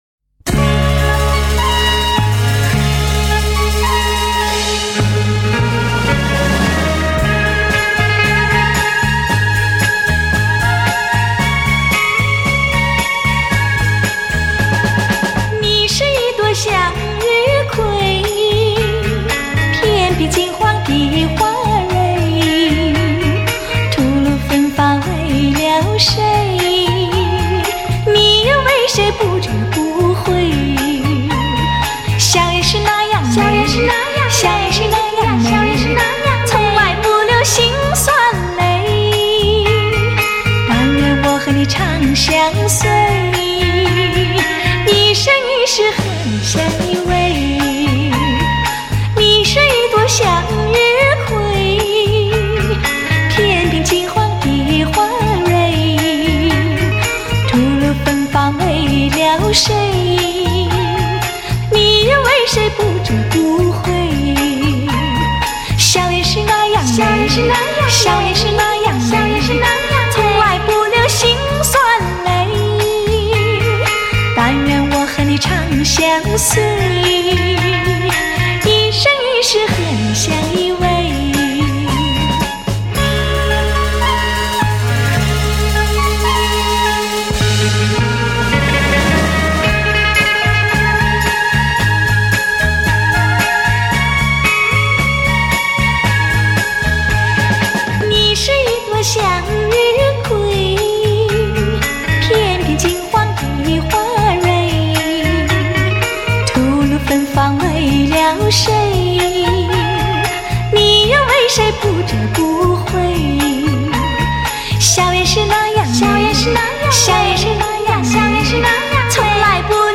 不带粉饰的清音雅韵 纯真雅洁的朴素情怀
首度以高保真CD正式出版 原始母带经高新科技原音处理
既保留了黑胶唱片的暖和柔美 也展现了数码唱片的精确清晰
让那甜蜜柔美的声音再度飘进你的心窝 萦牵你的心弦